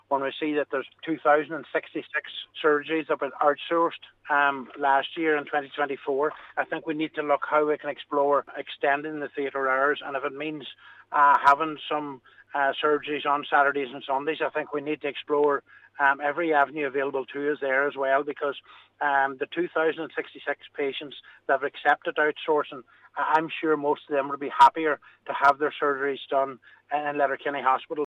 Speaking at a Regional Health Forum meeting last week, Cllr Ciaran Brogan said theatre hours should be extended into weekends to make space.